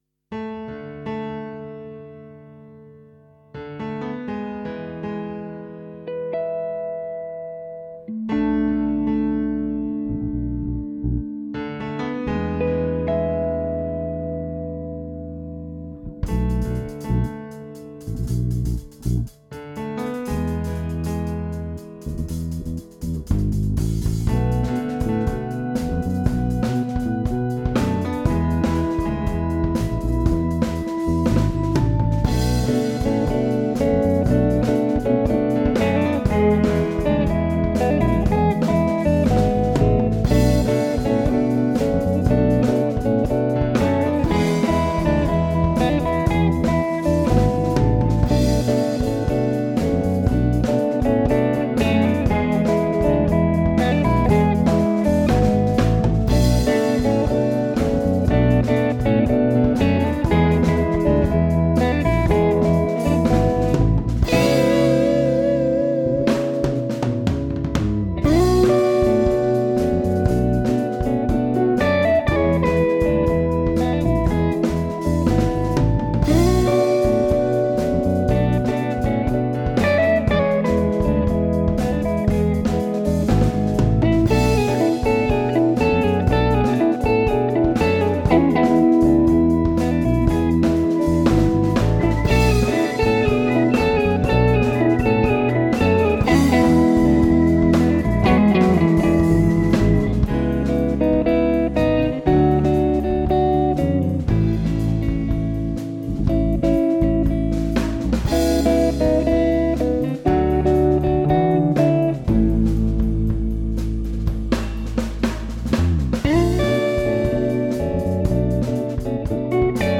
Some old demo tracks (supposed to be gathered in our second demo, “No One Came Back”), reloaded with a couple of changes: new drums tracks, new mix and a few edits from 2015 – still to be recorded properly with a click one day though!
• Bass Guitar: Fender Jazz Bass (with Roland V-Bass for FX).
• Drums: Roland V-Drums triggering the Addictive Drums plug-in, by XLN Audio.